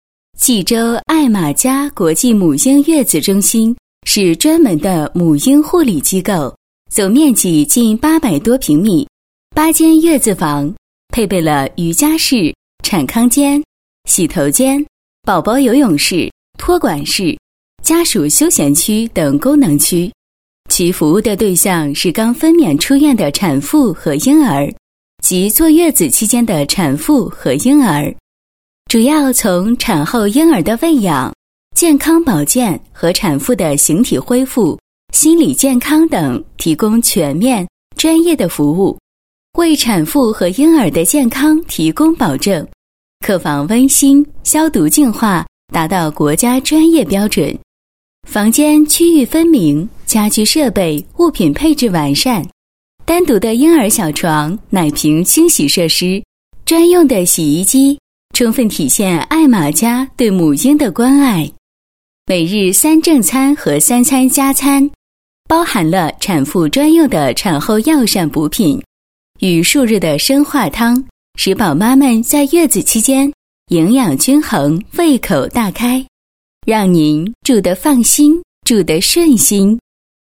女国语132